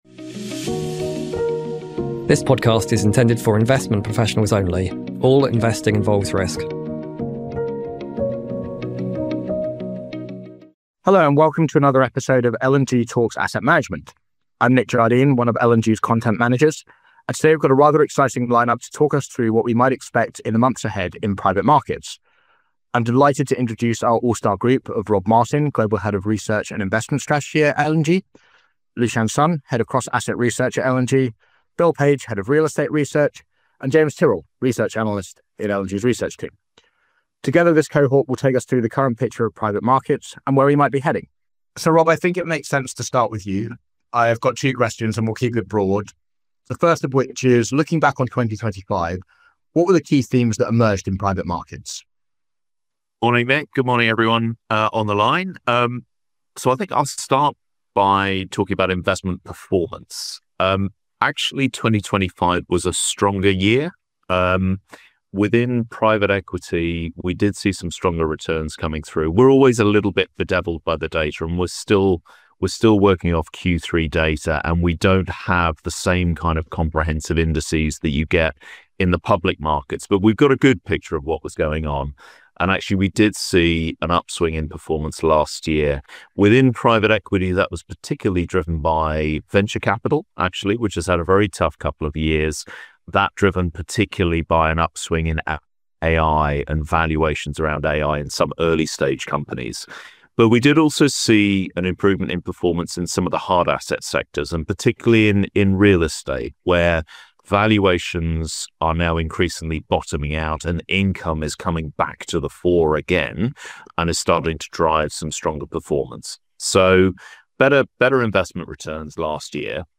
What do the next 12 months have in store for private markets? That’s the question our panel aims to answer on this week’s podcast. Discussion focused on what influenced private markets in 2025 and what key themes we see emerging with 2026 already well under way. We also looked at specific sectors in more detail, delving into real estate, digital infrastructure and private credit.